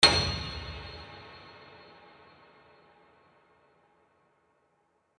HardPiano
c7.mp3